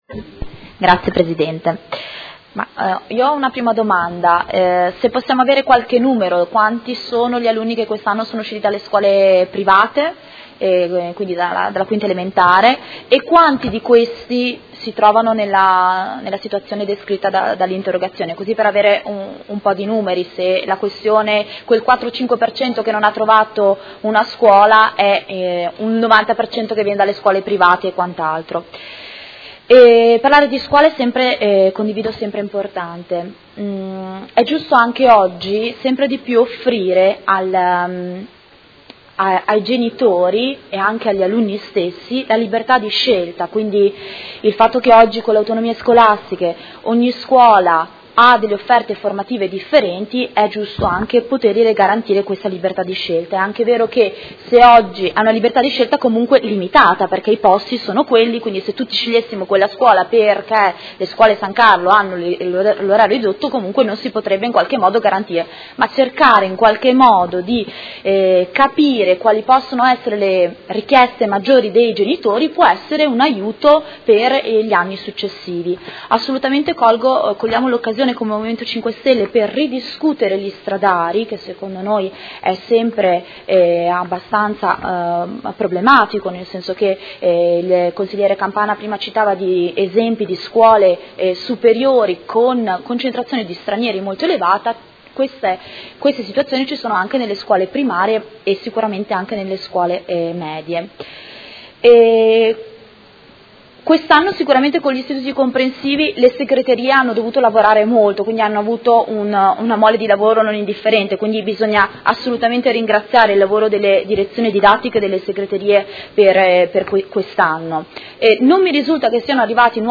Elisabetta Scardozzi — Sito Audio Consiglio Comunale
Seduta del 6/04/2017 Interrogazione del Consigliere Galli (FI) avente per oggetto: Caos nelle liste d’attesa per l’iscrizione alle Scuole Medie modenesi; quello che era facile prevedere è accaduto; a Natale alcuni studenti avevano già un banco, oggi, a due mesi di distanza molti altri non hanno neppure l’illusione di quel banco.